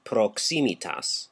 Ääntäminen
Ääntäminen : IPA : /kə.ˈnɛk.ʃən/ US : IPA : [kə.ˈnɛk.ʃən]